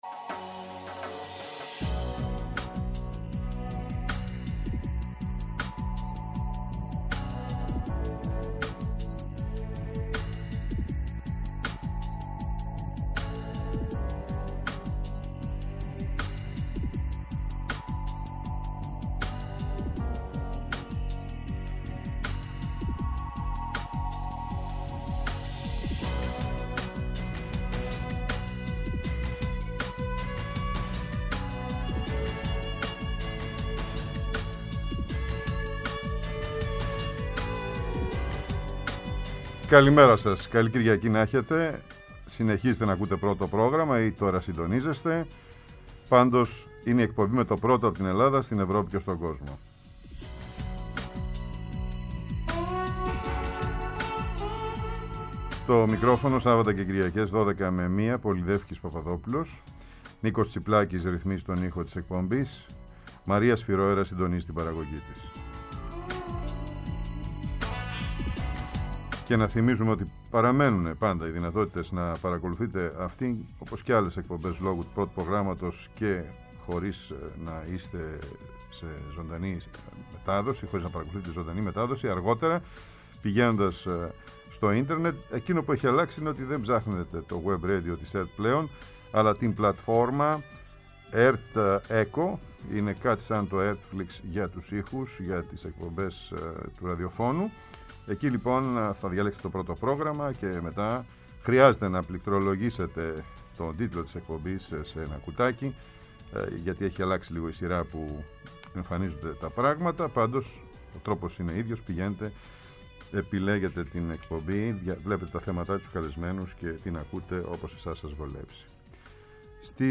Σήμερα, η κρίσιμη κατάσταση στο εσωτερικό των ΗΠΑ, οι αμφιλεγόμενες αποφάσεις του Ανωτάτου Δικαστηρίου, η αύξηση των ανισοτήτων, η μεγάλη κοινωνική και πολιτική πόλωση, τα ταυτοτικά ζητήματα, το ανολοκλήρωτο New Deal του Μπάιντεν, ο υψηλός πληθωρισμός και οι επερχόμενες ενδιάμεσες εκλογές με καλεσμένο τον Κωνσταντίνο Αρβανιτόπουλο, καθηγητή Διεθνών Σχέσεων στο Πάντειο, π. υπουργό και κάτοχο έδρας Κ. Καραμανλής στη Σχολή Φλέτσερ του πανεπιστημίου Ταφτς.